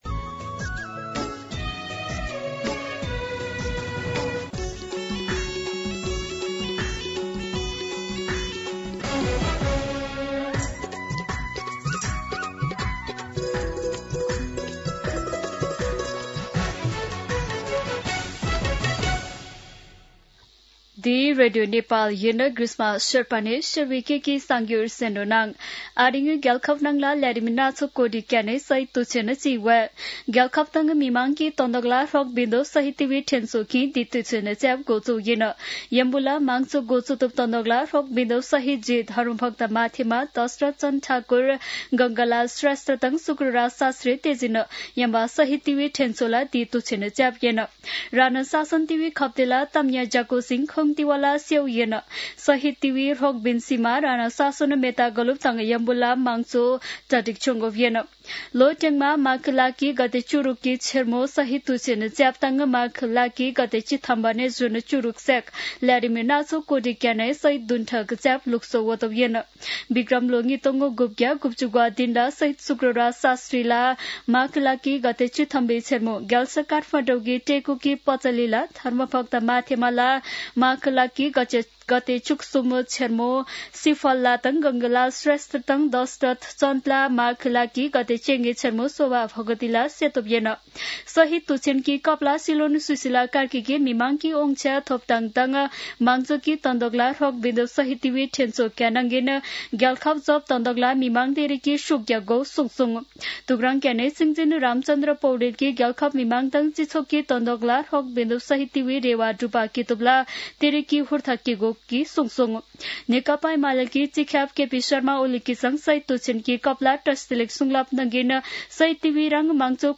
शेर्पा भाषाको समाचार : १६ माघ , २०८२